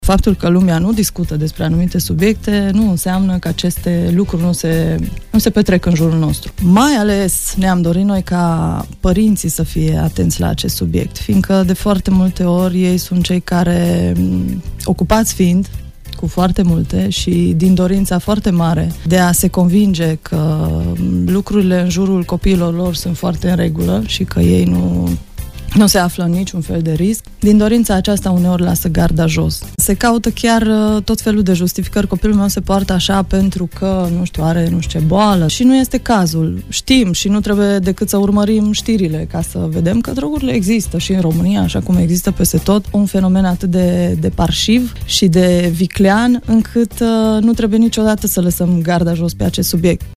Invitată la RTM